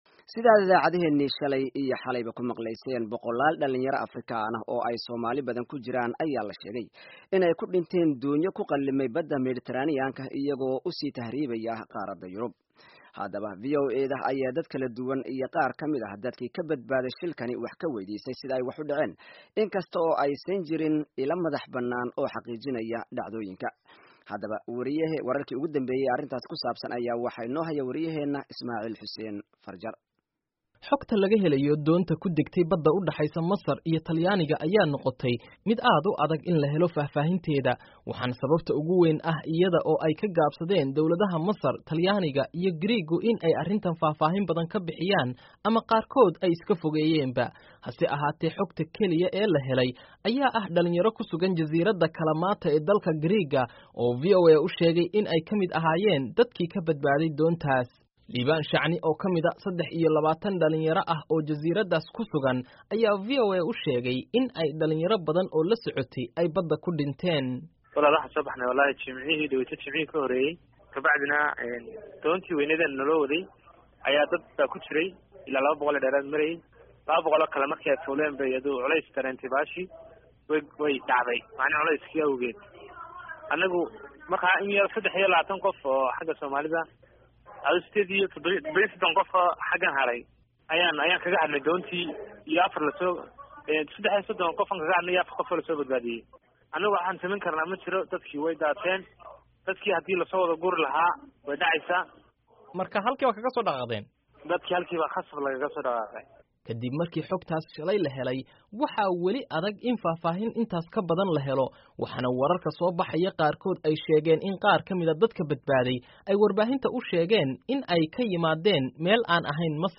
Warbixin Tahriibayaasha